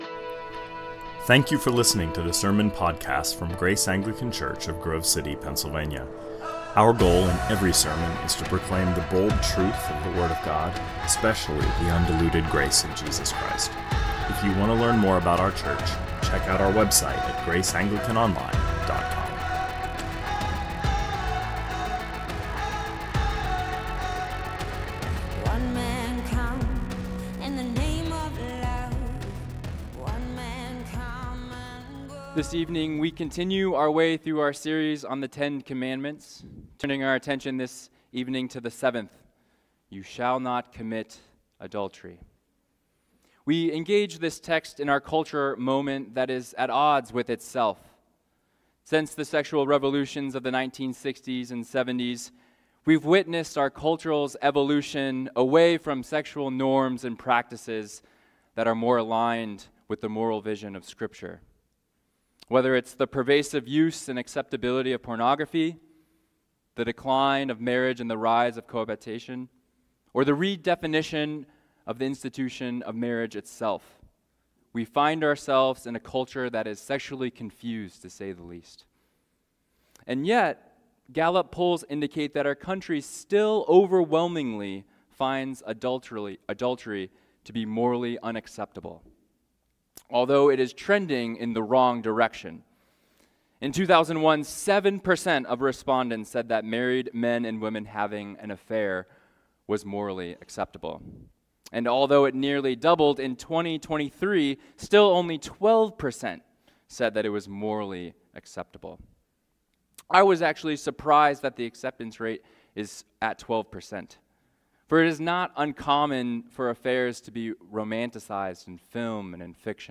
2024 Sermons